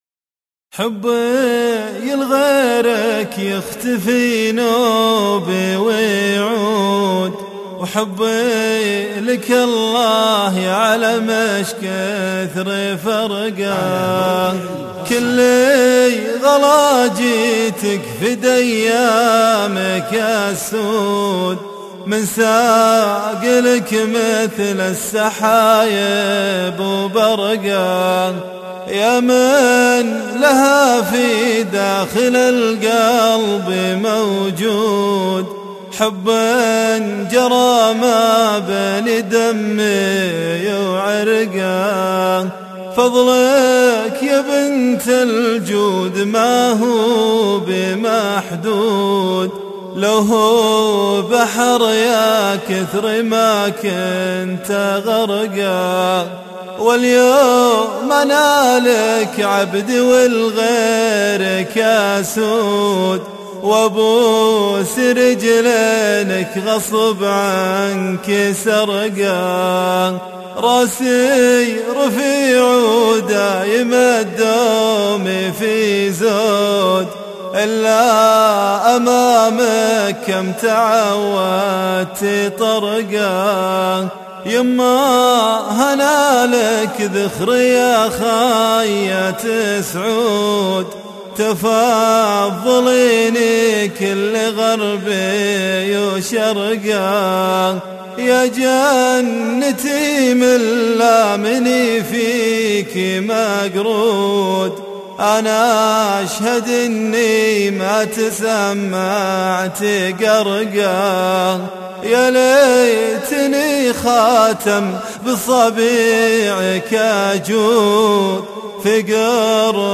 شيله